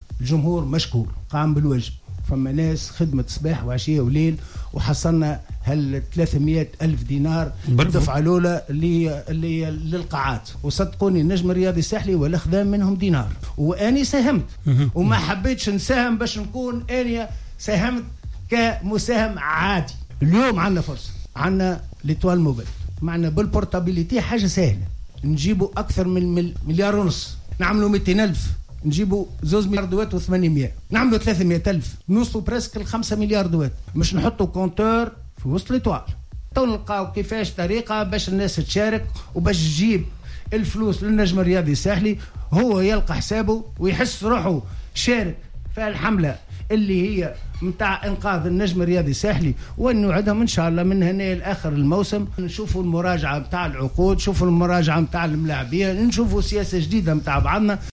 أفاد رئيس النجم الساحلي الدكتور رضا شرف الدين لدى إستضافته في حصة "Cartes sur table" أن جماهير النجم قد أثبتت أنها سند قوي للفريق في كل الفرص آخرها نجاح حملة دعم رياضات القاعات.